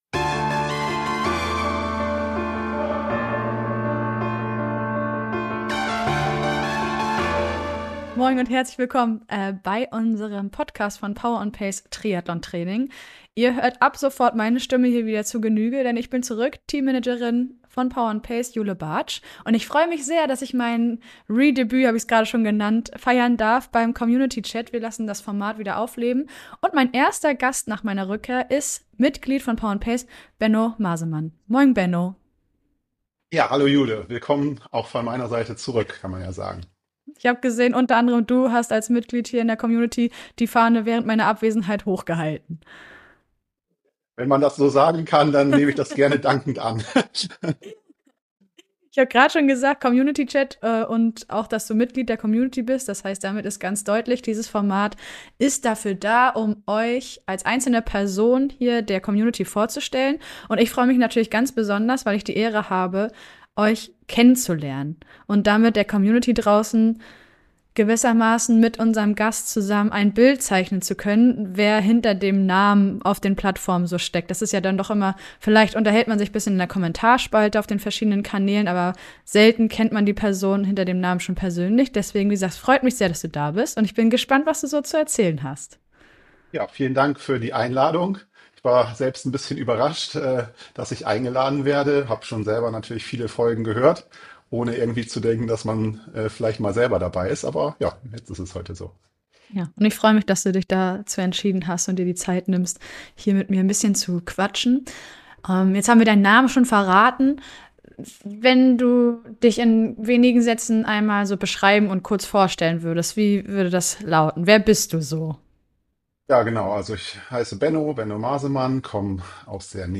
Er spricht sehr transparent, ehrlich und direkt über seine aktuelle Vorbereitung auf die erste Langdistanz bei der Challenge Roth und die damit verbundene organisatorische Meisterleistung, die es bedarf.